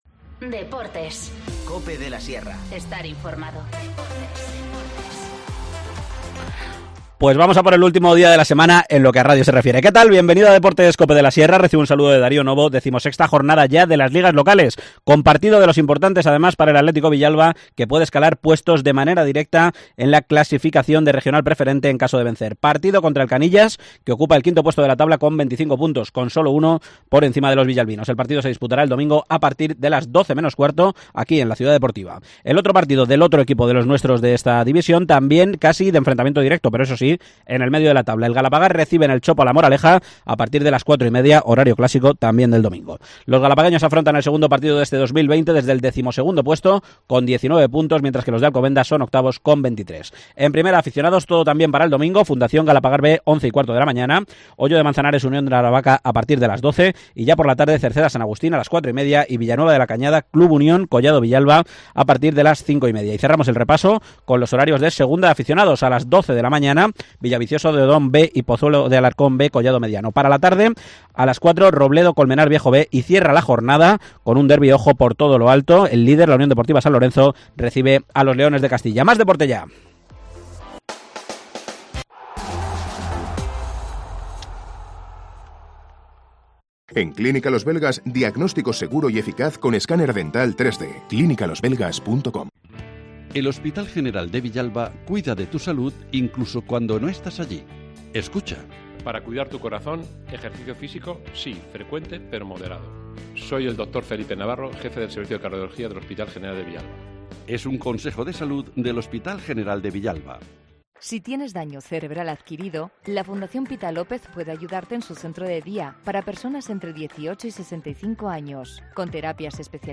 AUDIO: Fin de semana de tenis en la Plaza de Toros de Valdemorillo. Nos lo cuenta el concejal de Deportes Miguel Partida.